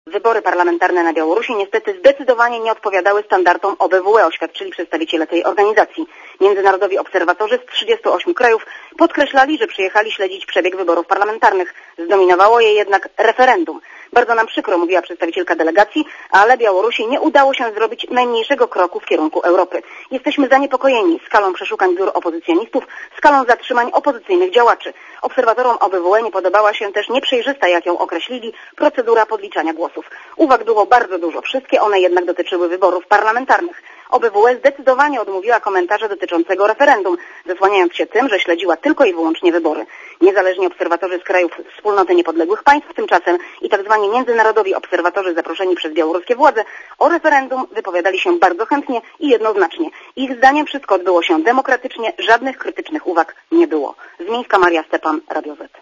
Relacja specjalnego wysłannika Radia ZET